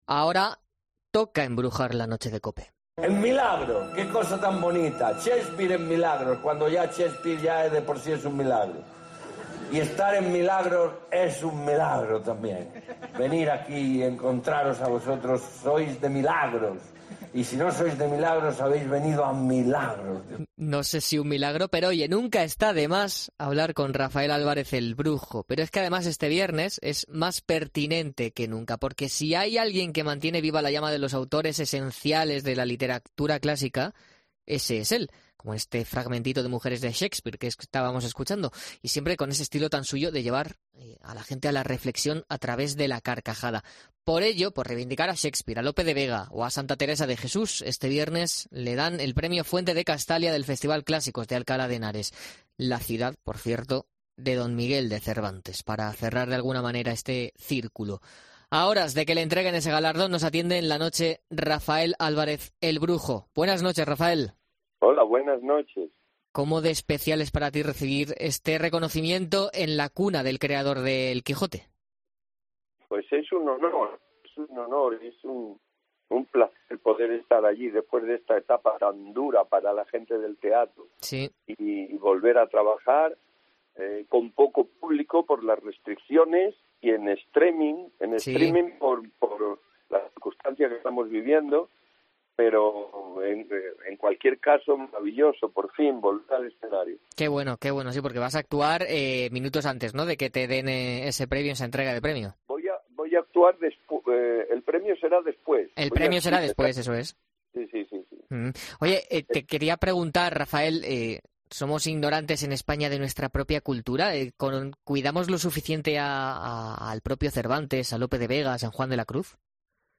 El actor y dramaturgo andaluz ha denunciado en 'La Noche' de COPE la escasa enseñanza de la literatura clásica en los colegios de nuestro país